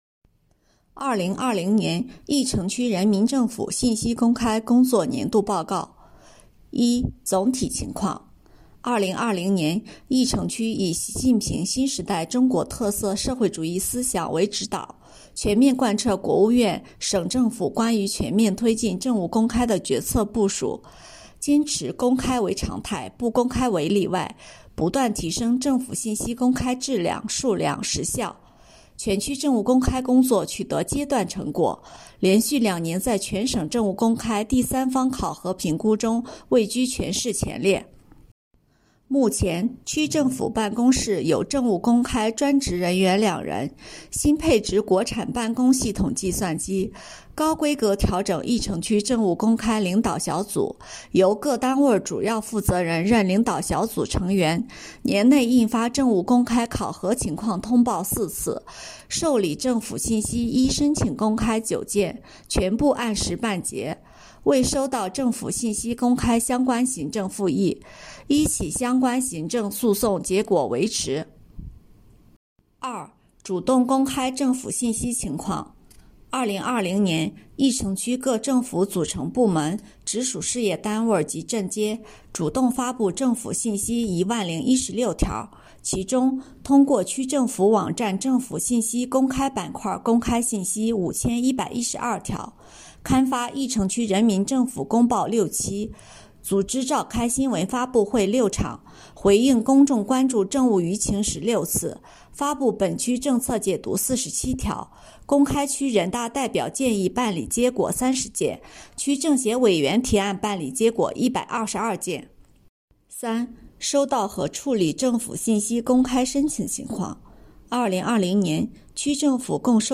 有声朗读：2020年峄城区人民政府信息公开工作年度报告